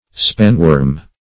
spanworm - definition of spanworm - synonyms, pronunciation, spelling from Free Dictionary
Search Result for " spanworm" : The Collaborative International Dictionary of English v.0.48: spanworm \span"worm`\ (sp[a^]n"w[^u]rm`), n. (Zool.) The larva of any geometrid moth, as the cankerworm; a geometer; a measuring worm.